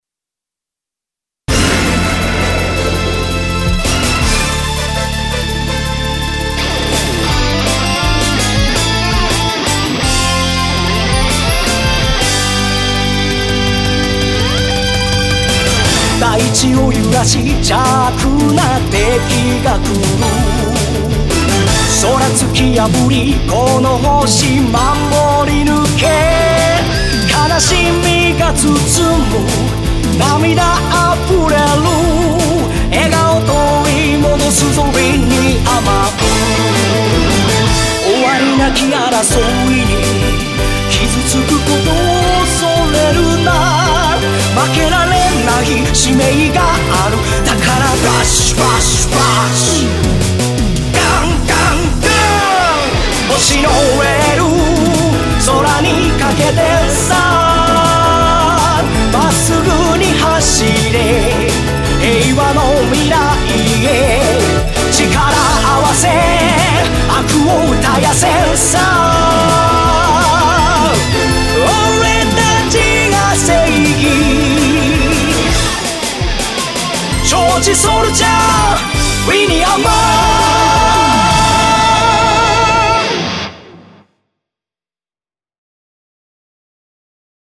ロックサウンドやシンセサイザーサウンドを多く取り入れた80年代～現在の戦隊モノ風をイメージした楽曲です。
ハードで熱いサウンドに最適です。